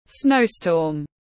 Snowstorm /ˈsnəʊ.stɔːm/